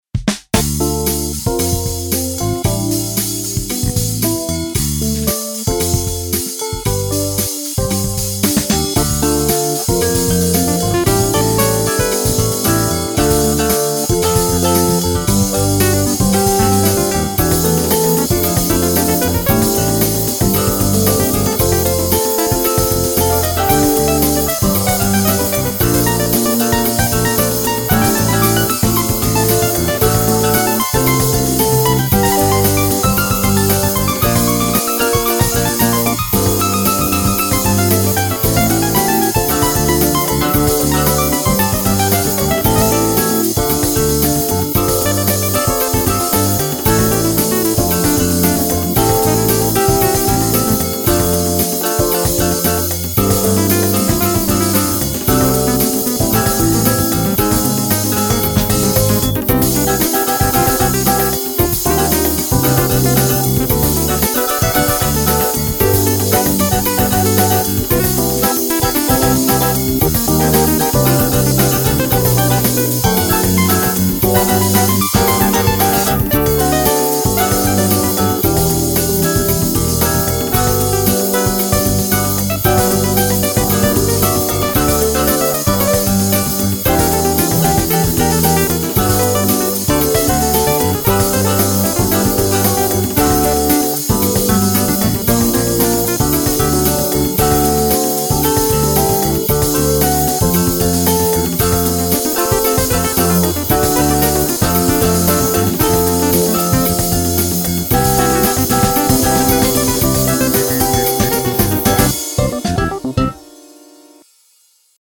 Dry AD Drums, Dry Trilogy bass, Dry Lounge lizard, Dry strat, Dry Waldorf Rocket through Kjareus autofilter